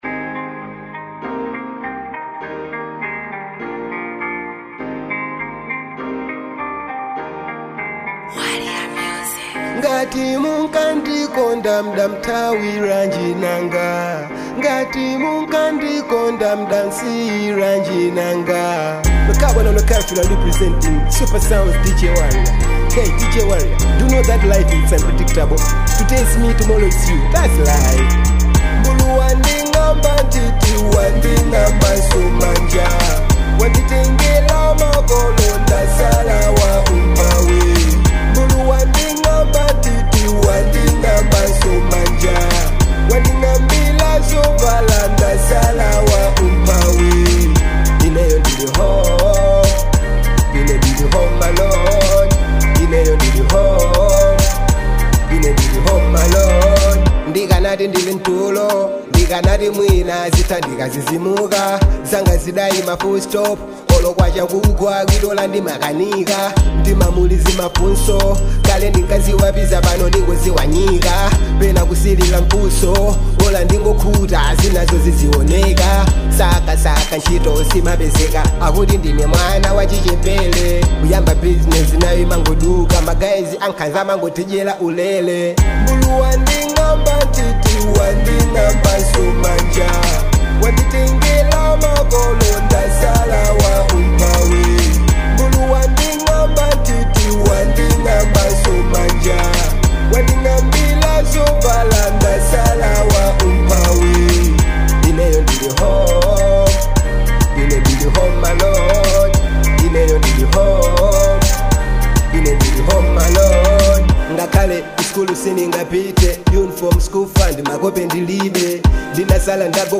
Genre : Reggie Dancehall